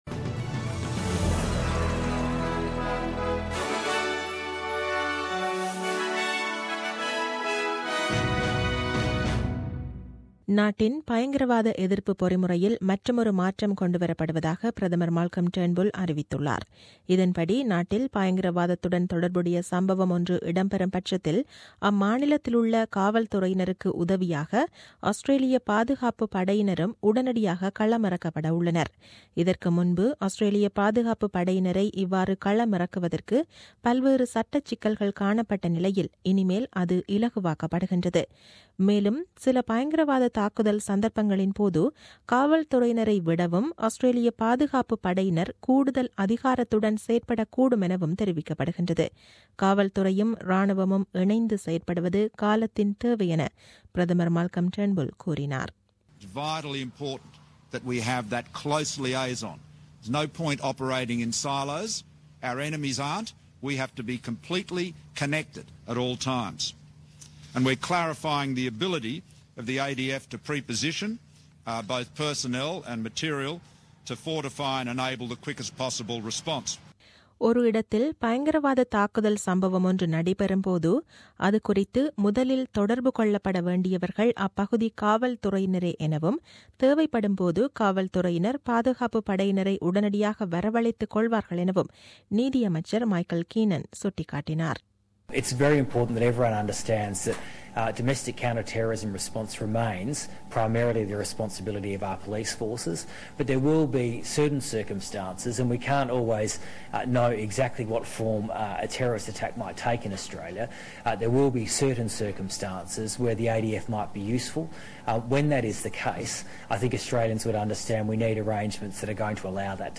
The news bulletin aired on 17 July 2017 at 8pm.